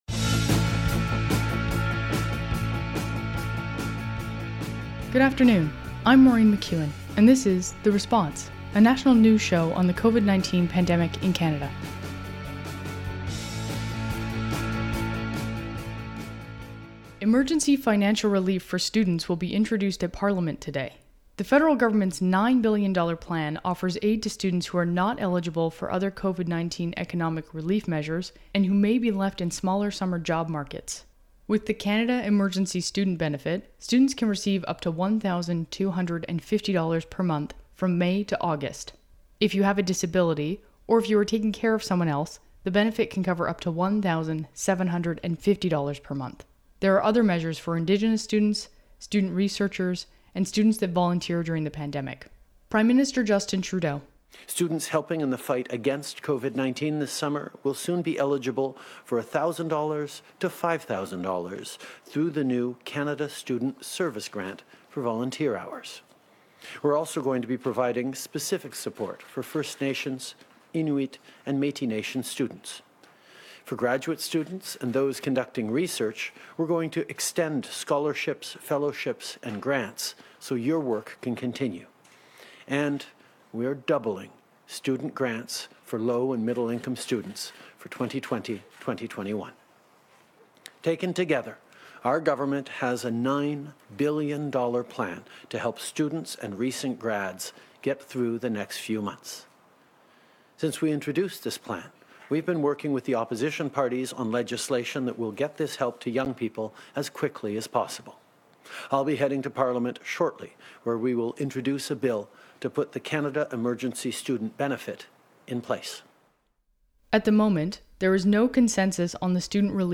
National News Show on COVID-19
Type: News Reports
192kbps Stereo